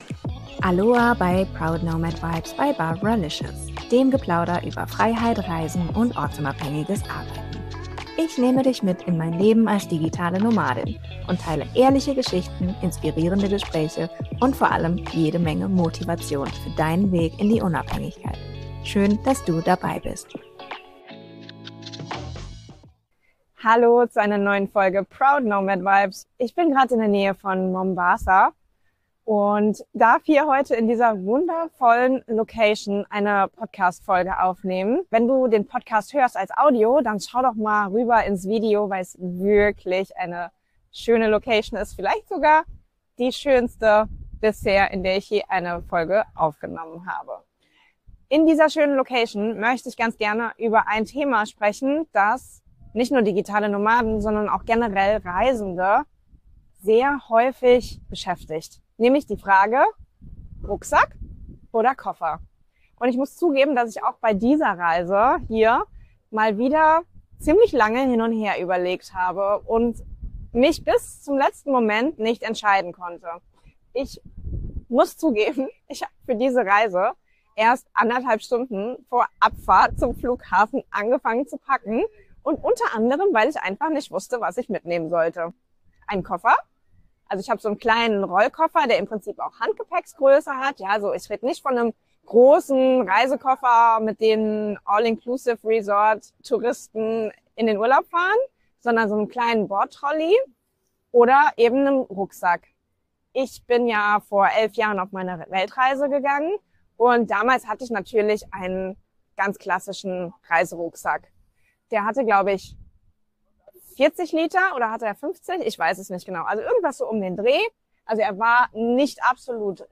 Solo-Folge
von der malerischen Küste nahe Mombasa